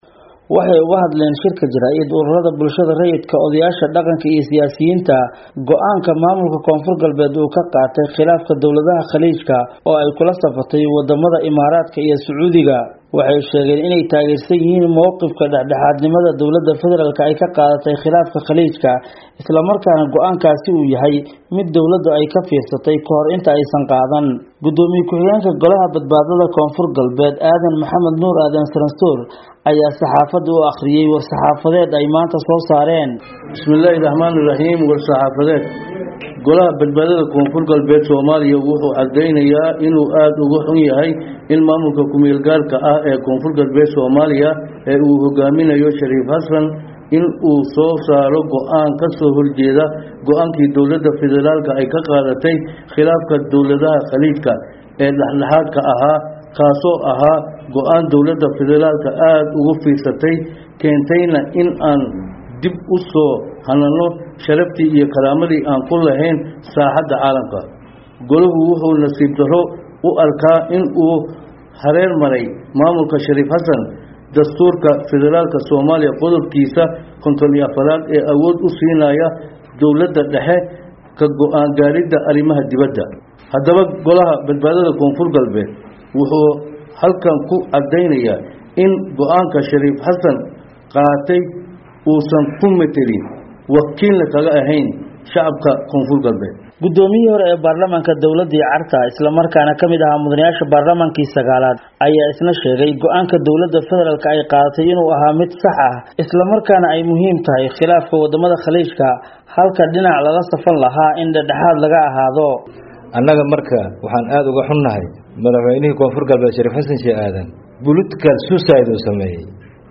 Dhegayso: Warbixin ku saabsan siyaasiyiin dhaliilay go'aanka maamulka K/Galbeed